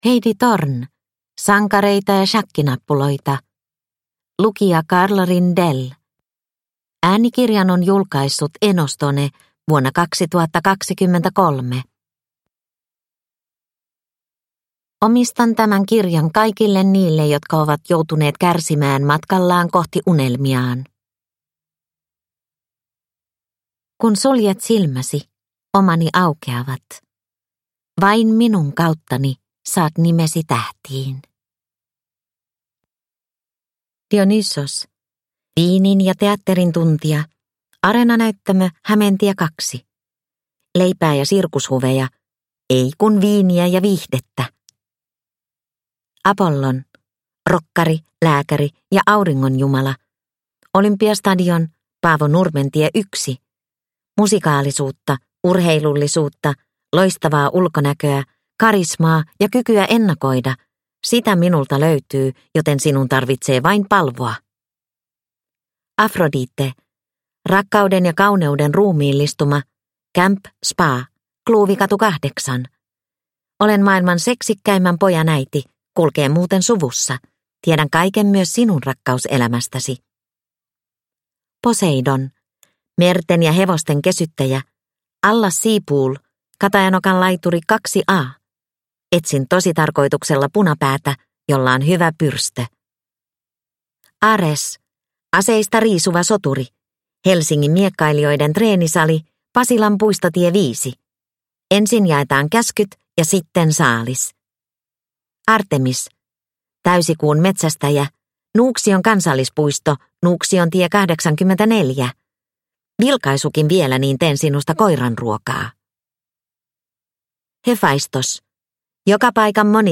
Sankareita ja shakkinappuloita – Ljudbok